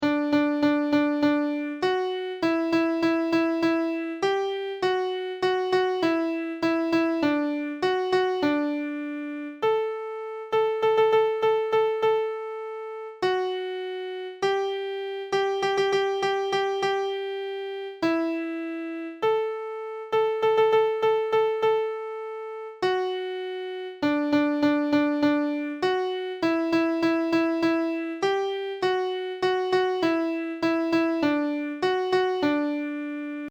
There are many variations of this story in song, although the melody remains about the same and the basic theme of the hunt (for bear, walrus, seal, etc.) runs through each variant. The words here are written phonetically; the "g" has a somewhat gutteral sound. The chorus portrays a kayak trip.